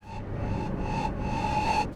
enemycome1.wav